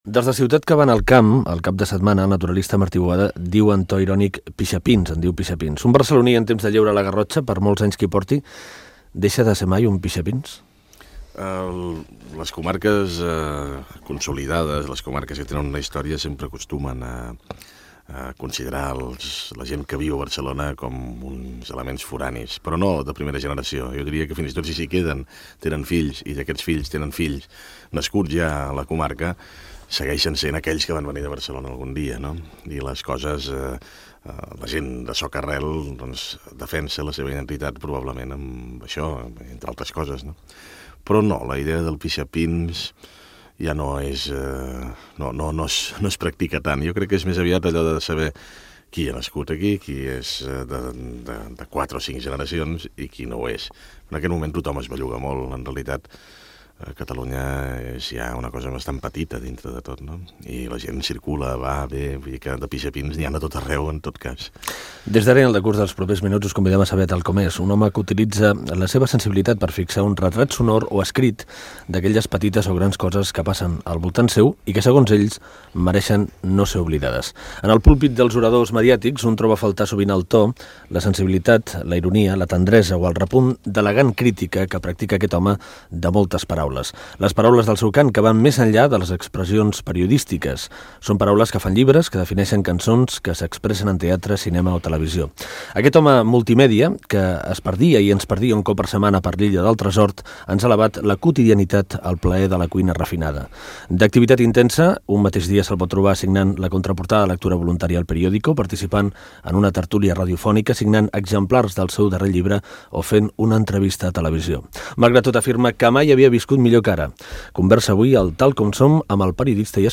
Fragment d'una entrevista al periodista i escriptor Joan Barril.
FM